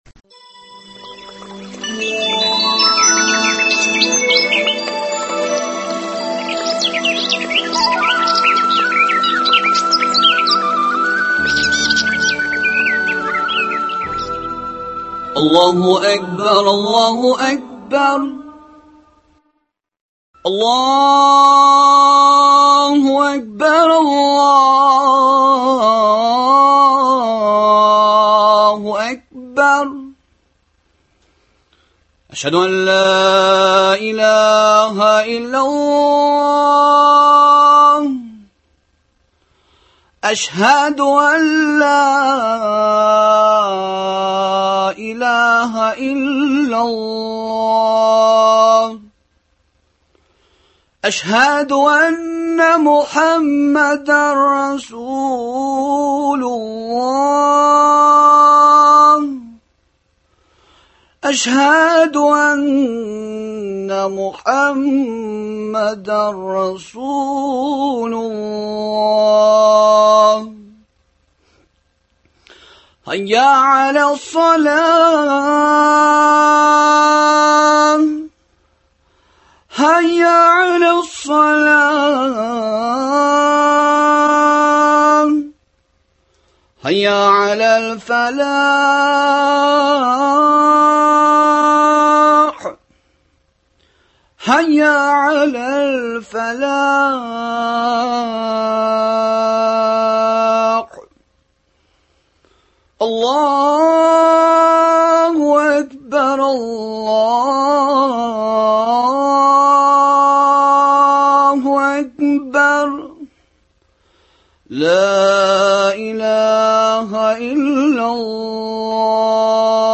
Ураза Гаете, аның хикмәтләре, фазыйләтләре турында әңгәмә.